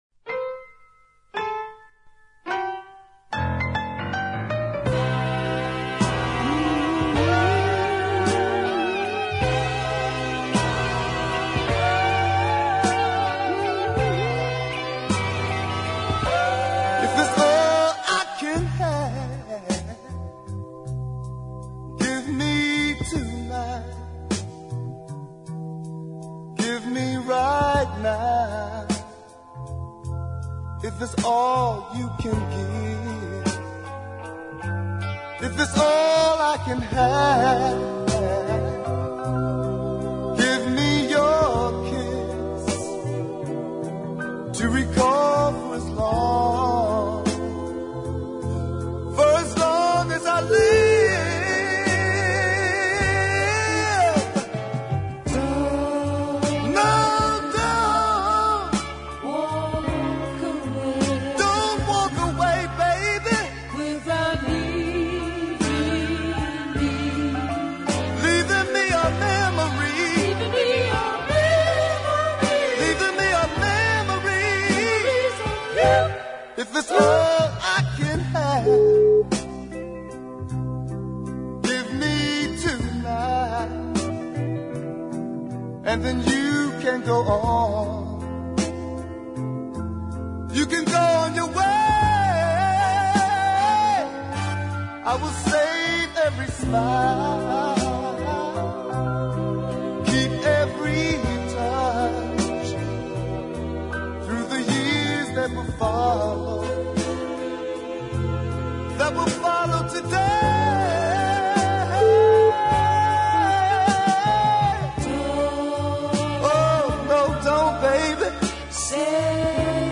Lovely gruff pitch too.